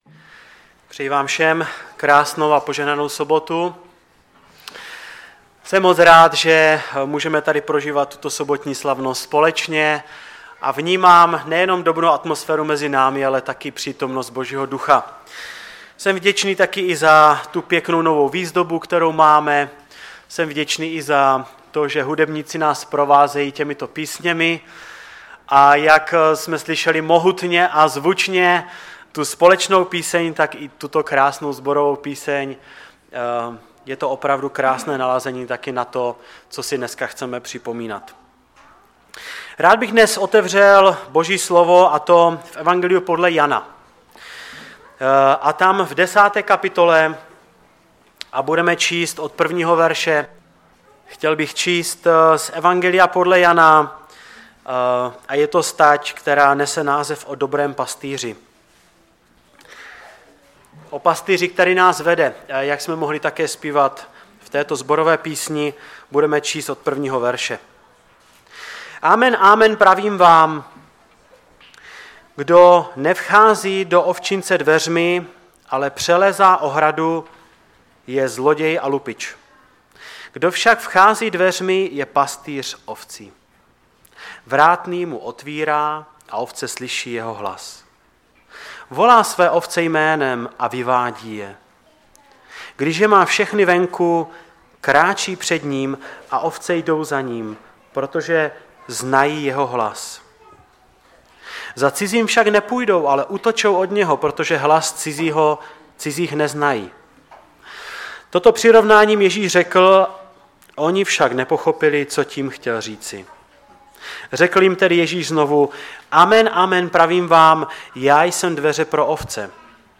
ve sboře Ostrava-Radvanice v rámci Památky Večeře Páně.
Kázání